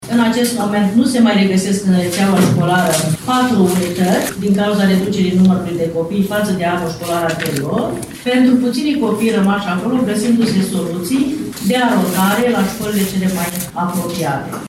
Aceste unități au fost închise din cauza diminuării semnificative a numărului de elevi, după cum a declarat astăzi inspectorul general GABRIELA MIHAI.